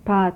These sound files were collected from a larger group of files located Archive Databases from the Phonetics Resources at UCLA.
Sound File #2 - File and visual below demonstrates the word, "Duck" as spoken in the Western Armenian dialect. pʰatʰ is the transcription of the word.